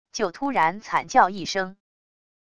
就突然惨叫一声wav音频